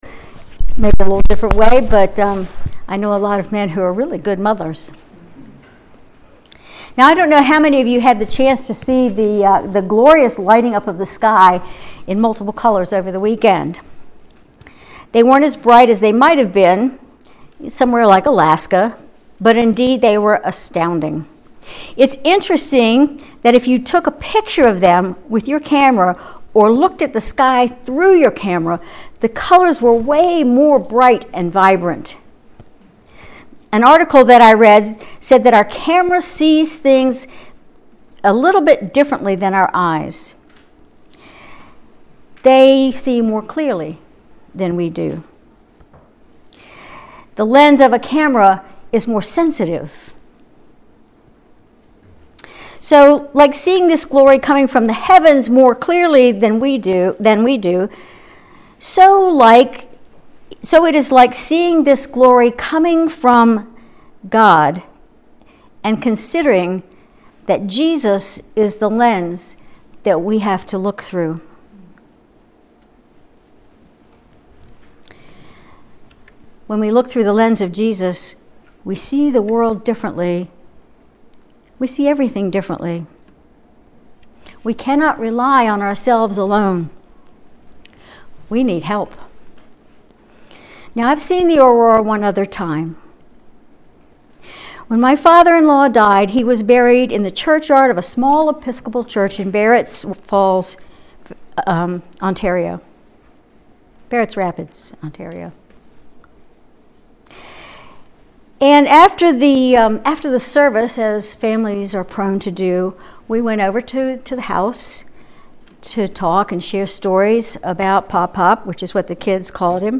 Sermon May 12, 2024